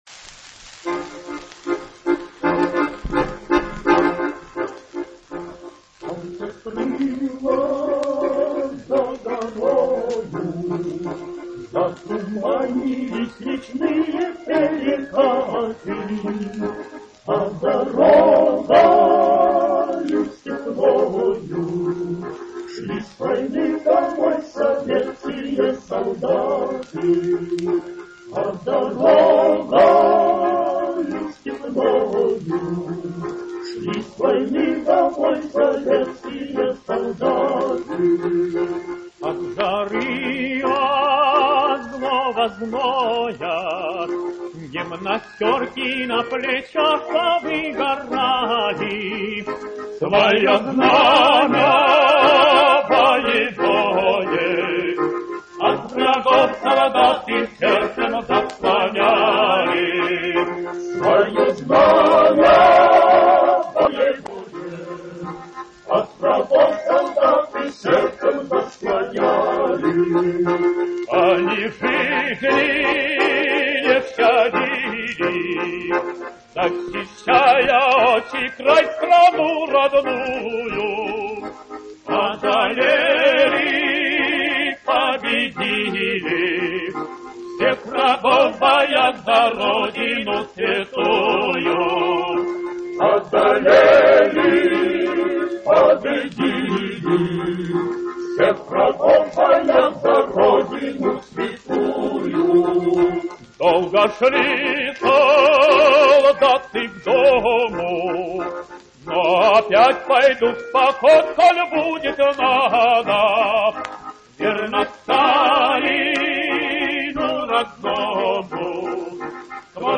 Ранний вариант известной песни.